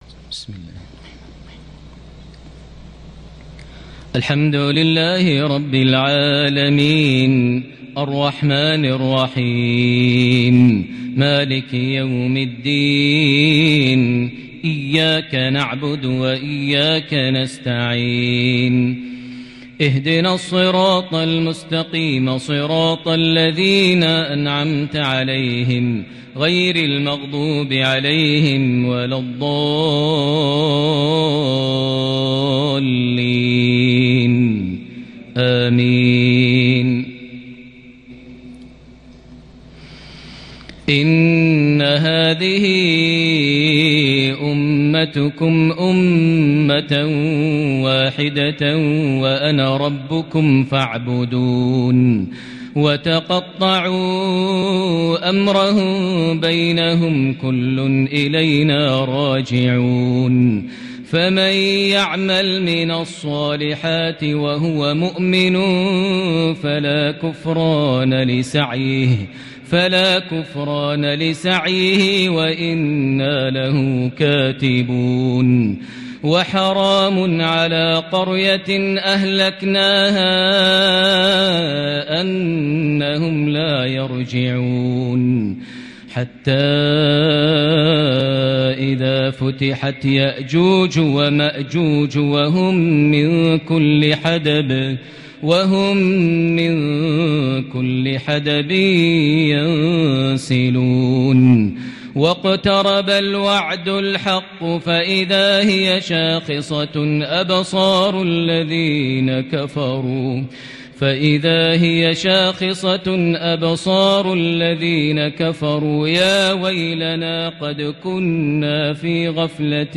عشائية كردية آسره من سورة الأنبياء (92-112) | 11 جمادى الأول 1442هـ > 1442 هـ > الفروض - تلاوات ماهر المعيقلي